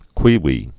(kwēwē)